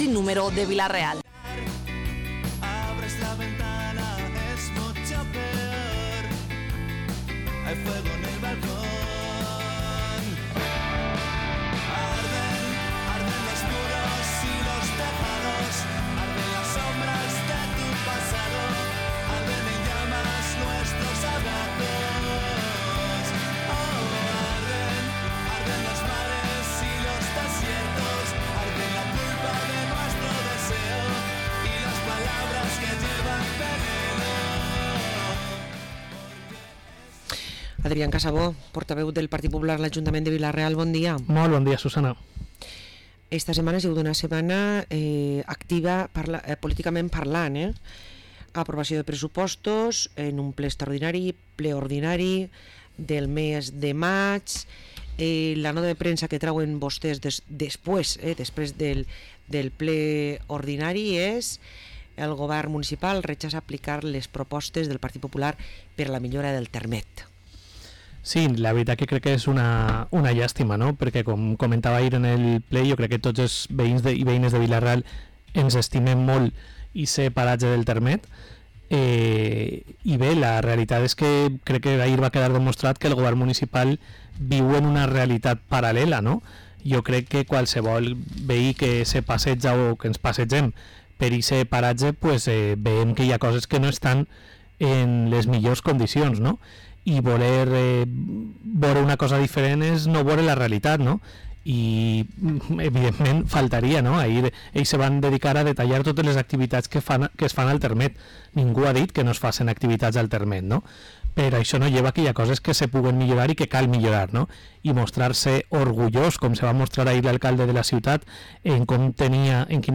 Parlem amb Adrián Casabó, portaveu i regidor del PP a l´Ajuntament de Vila-real